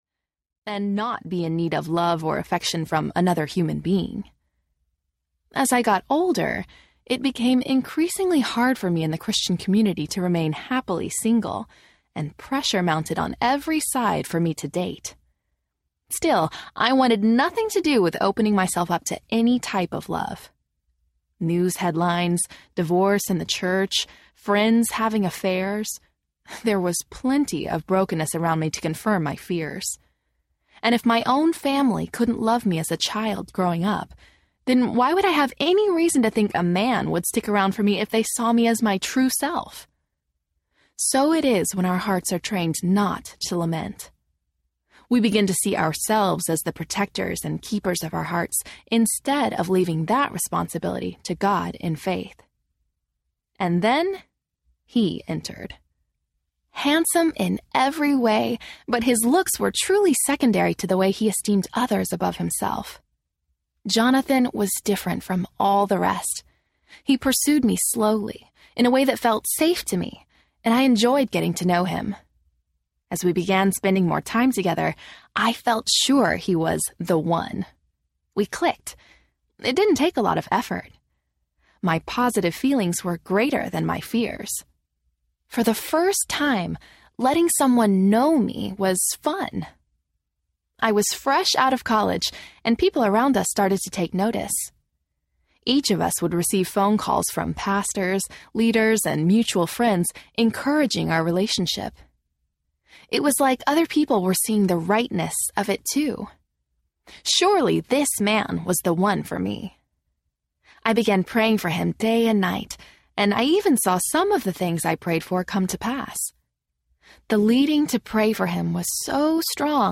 No More Faking Fine Audiobook